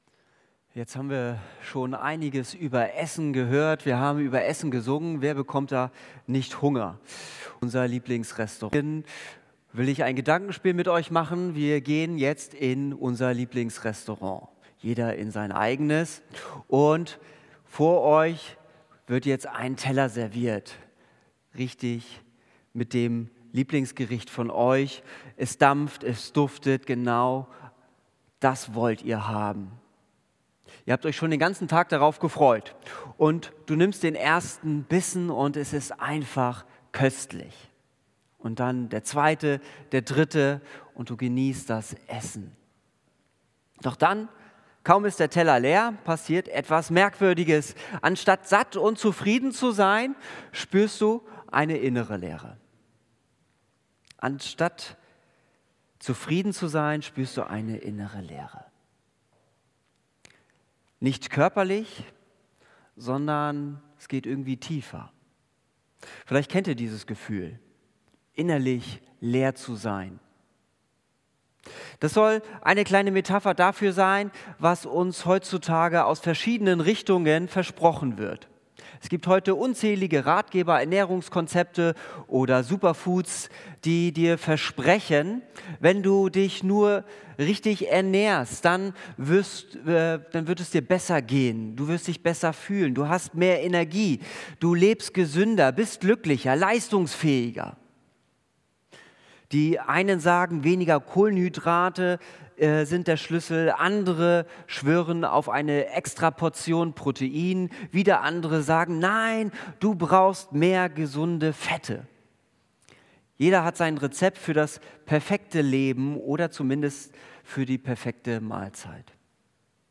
Predigt Wovon lebst du?